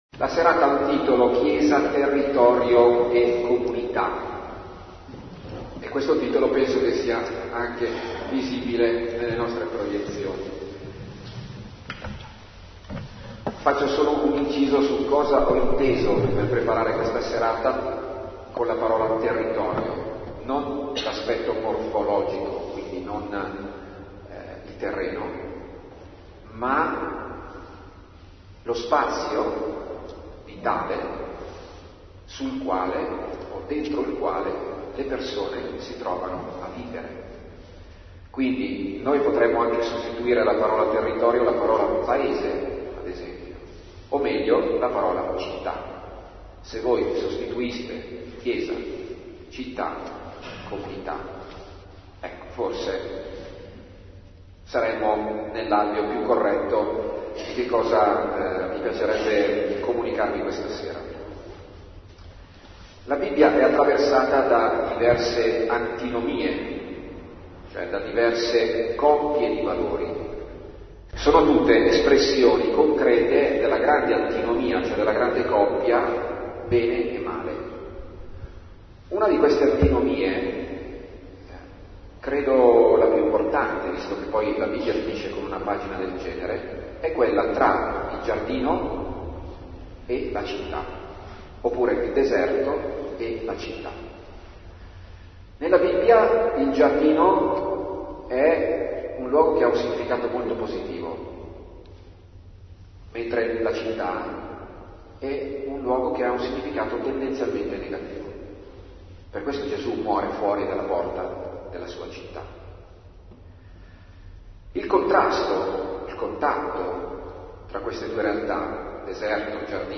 Lectio Quaresima 2018